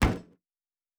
pgs/Assets/Audio/Sci-Fi Sounds/MISC/Metal Box Impact 2_1.wav
Metal Box Impact 2_1.wav